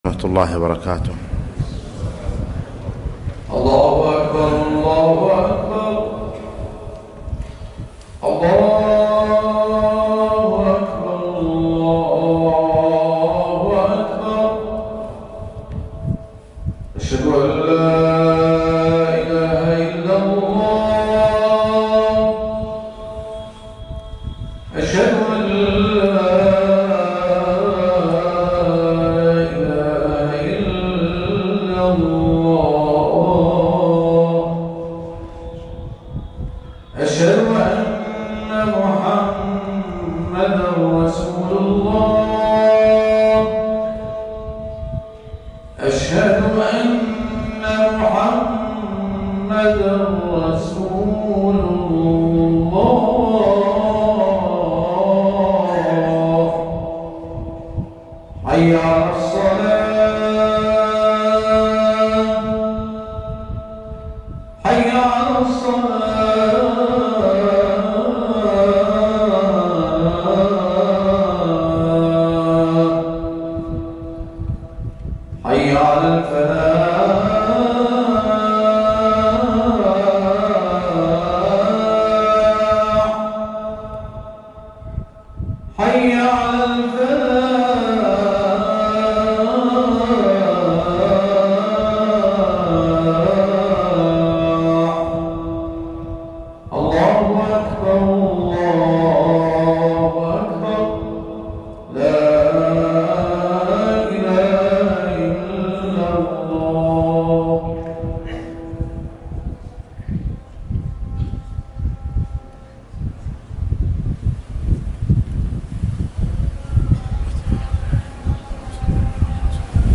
خطبة - السلامة في خمس